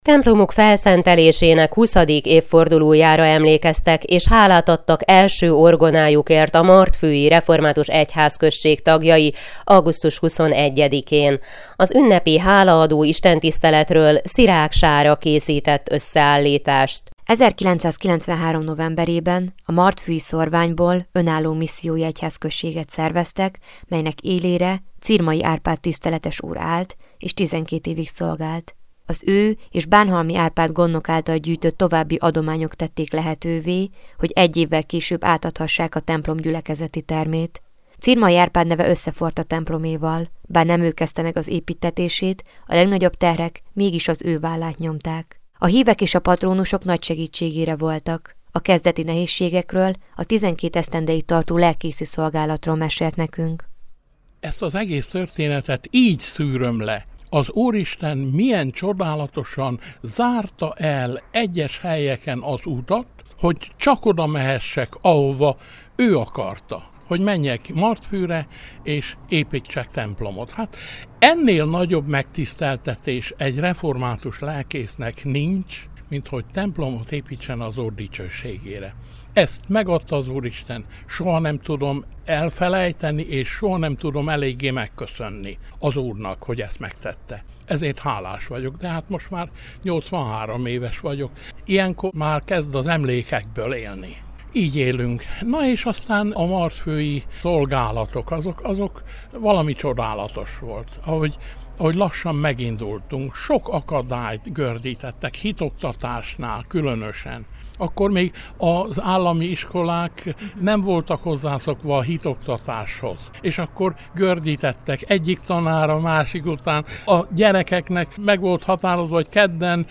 Ünnepi istentisztelet Martfűn - hanganyaggal